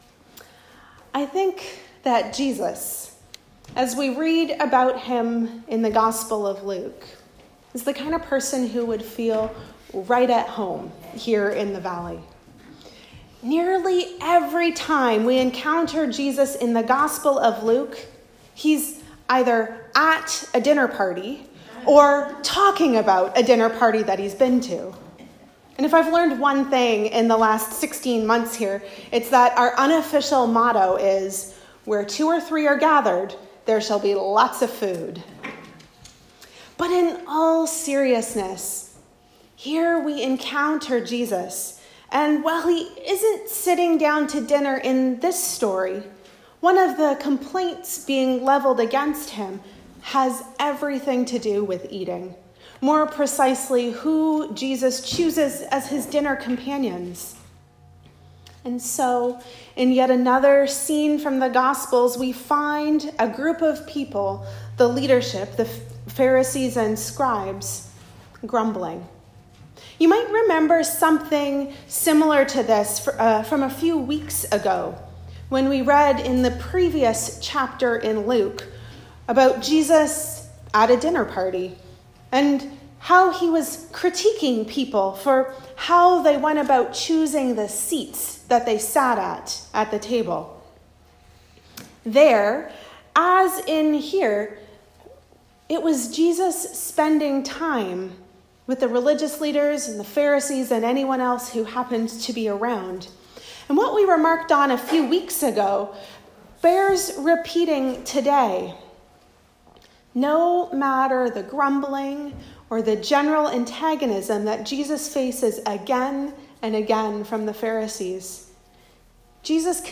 Sermons | Parish of the Valley